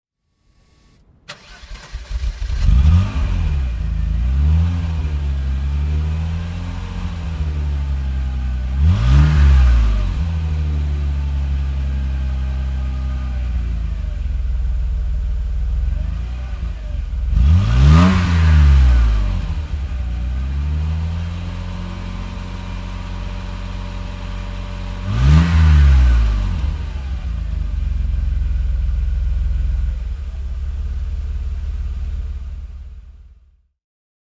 Mercedes Benz W25 (1934) - Roland Aschs Vorbeifahrt am Klausenrennen 2013 mit Getriebeproblemen